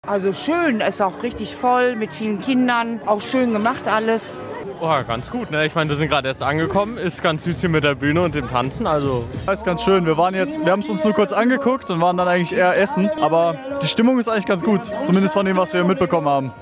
Auf dem Friedrich-Ebert-Platz ging am Wochenende die Post ab.
Stimmung